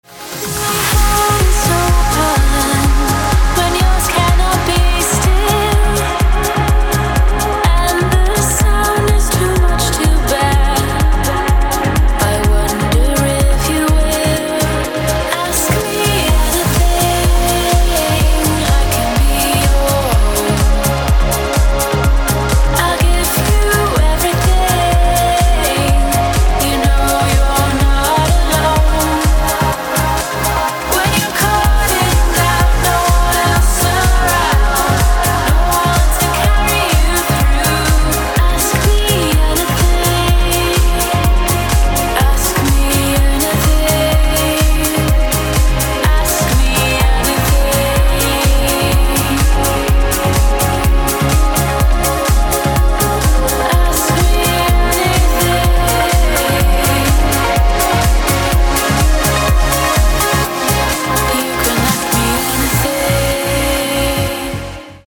• Качество: 256, Stereo
женский вокал
dance
электронная музыка
спокойные
красивый женский голос
Trance
vocal trance